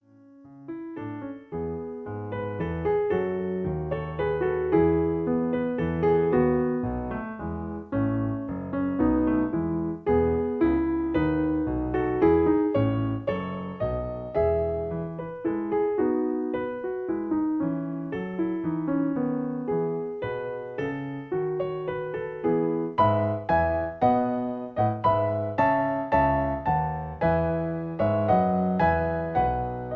In A. Piano Accompaniment